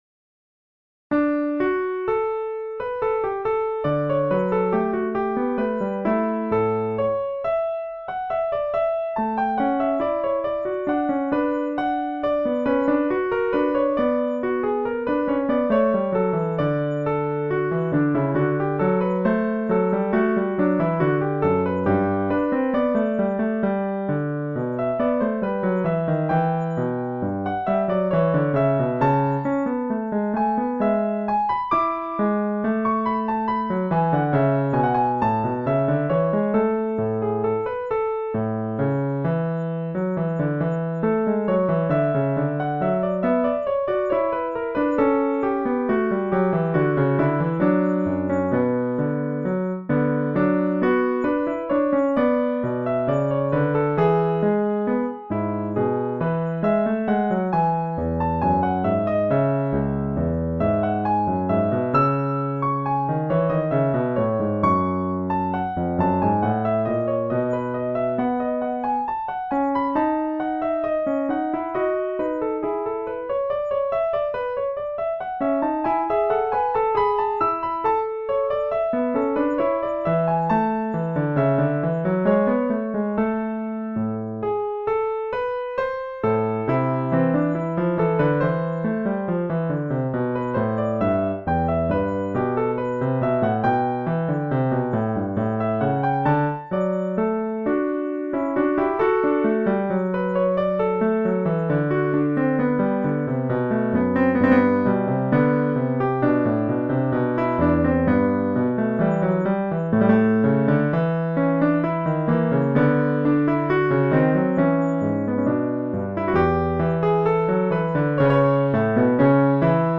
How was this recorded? i) this is me live, playing a Baroque-style